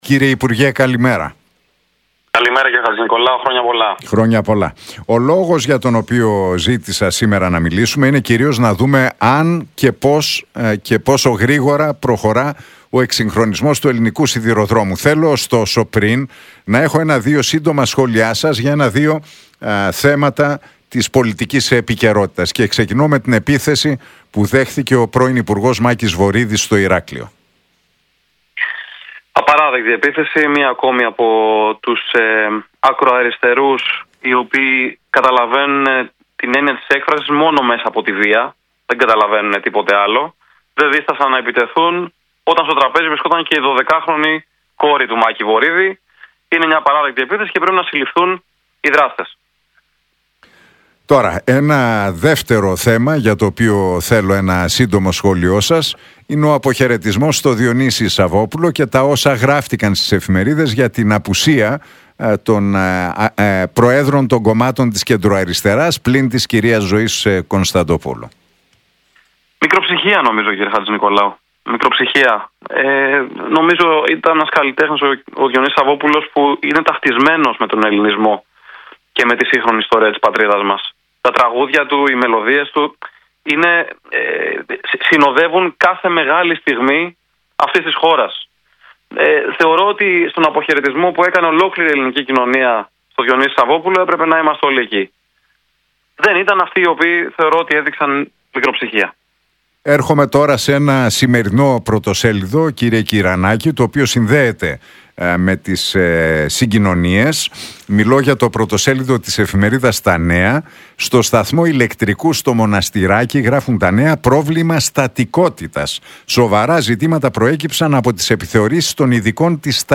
Για τα έργα εκσυγχρονισμού στον ελληνικό σιδηρόδρομο, την αντικατάσταση των παλαιών λεωφορείων και τρόλεϊ και τα έργα στον Προαστιακό μίλησε, μεταξύ άλλων, ο αναπληρωτής υπουργός Μεταφορών, Κωνσταντίνος Κυρανάκης στην εκπομπή του Νίκου Χατζηνικολάου στον Realfm 97,8.